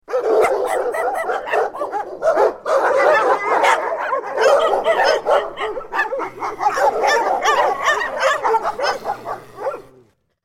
جلوه های صوتی
دانلود صدای سگ 15 از ساعد نیوز با لینک مستقیم و کیفیت بالا
برچسب: دانلود آهنگ های افکت صوتی انسان و موجودات زنده دانلود آلبوم صدای انواع سگ از افکت صوتی انسان و موجودات زنده